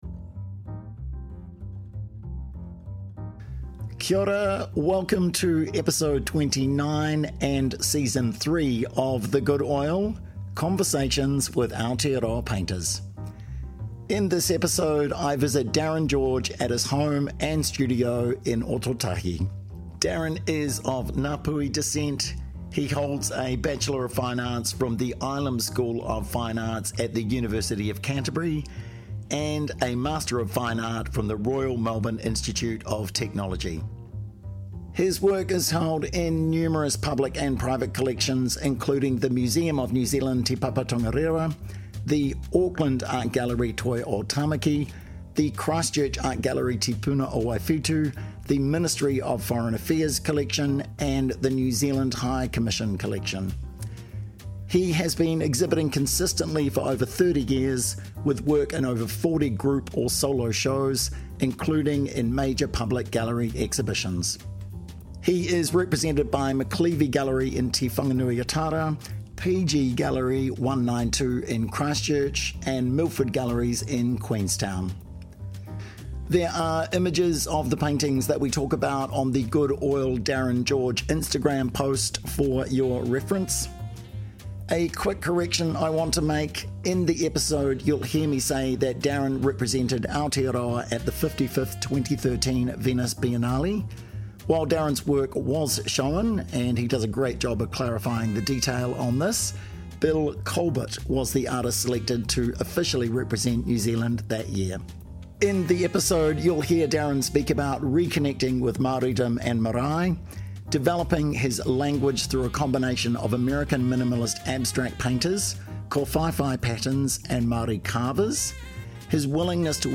The Good Oil is dedicated to long form conversations with Aotearoa / New Zealand painters about their lives and practices.